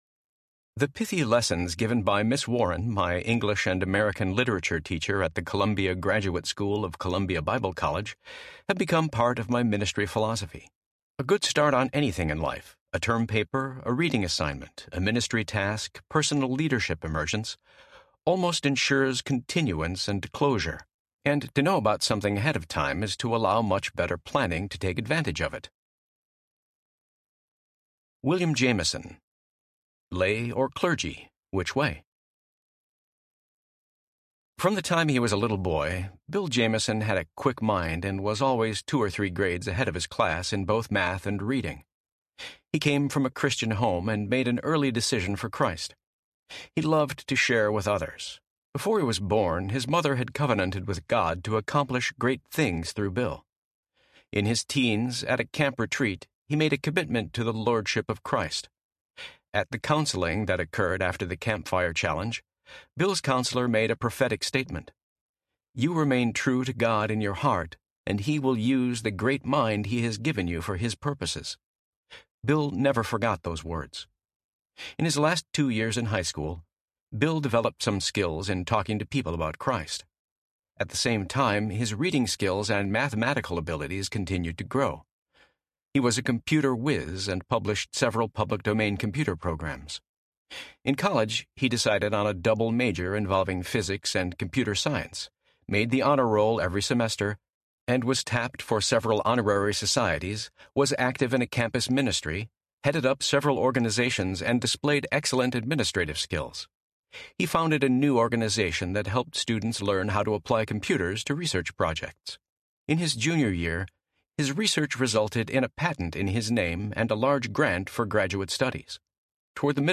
The Making of a Leader Audiobook
Narrator
6.2 Hrs. – Unabridged